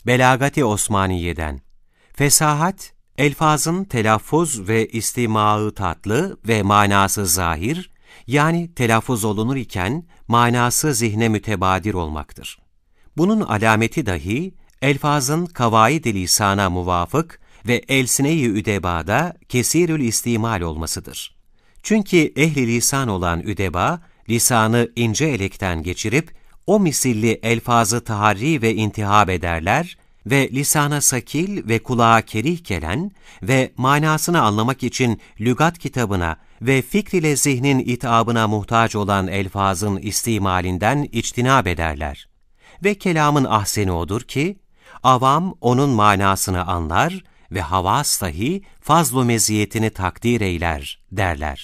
Osmanlıca Seslendirme
Erkek Ses